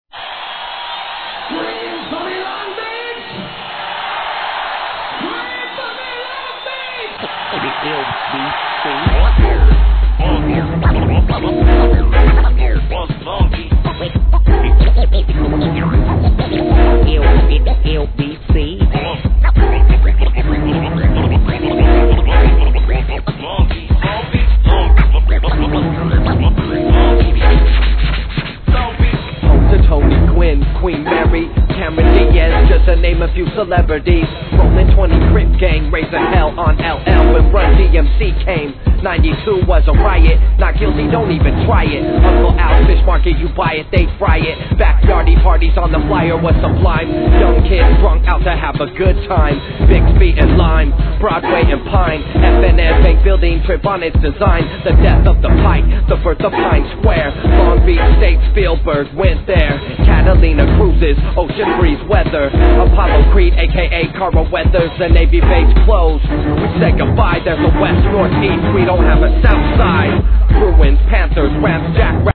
HIP HOP/R&B
ストリングスを大胆に用いたトラックにスクラッチをはめ込むスリリングな逸品!